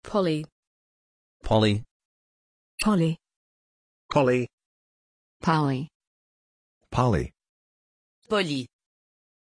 Pronunciation of Polly
pronunciation-polly-en.mp3